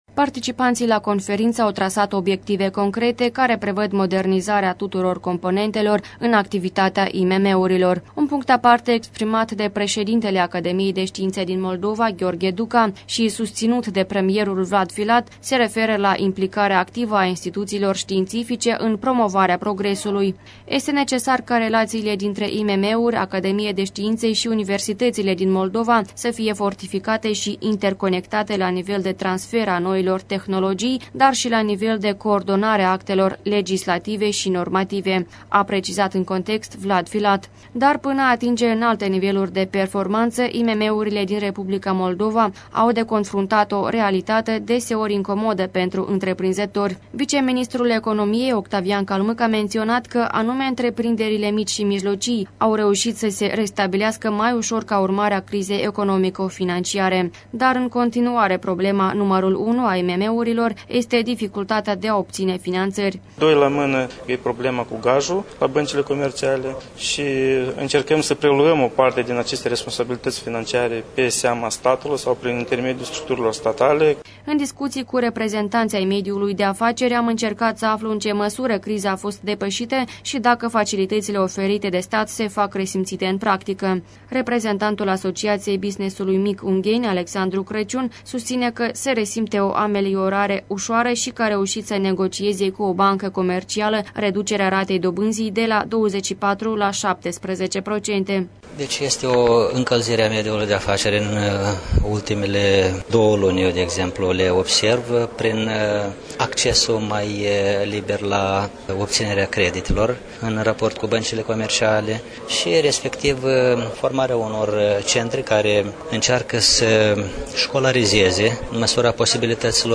Un reportaj de la conferinţă